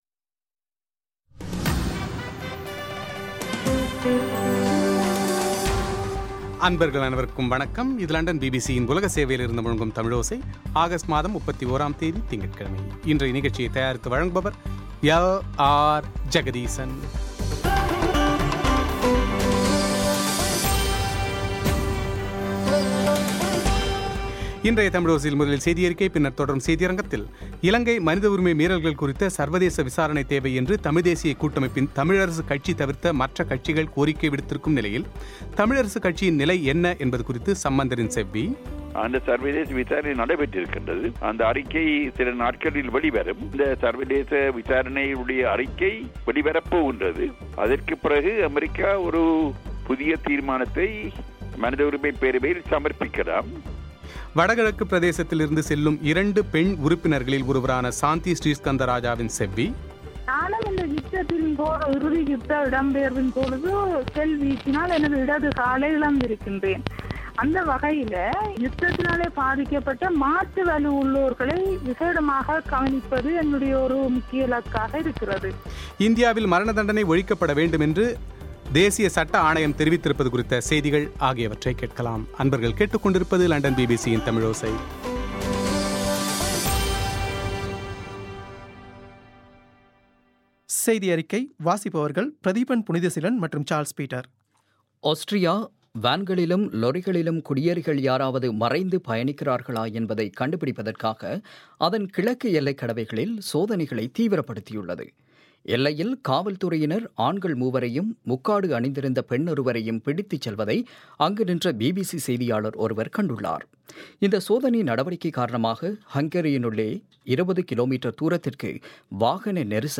இலங்கை மனித உரிமை மீறல்கள் குறித்த சர்வதேச விசாரணை தேவை என்று தமிழ்தேசியக் கூட்டமைப்பின் தமிழரசு தவிர்த்த கட்சிகள் கோரிக்கை விடுத்துள்ள நிலையில், இதில் தமிழரசு கட்சியின் நிலை என்ன என்பது குறித்த சம்பந்தரின் செவ்வி;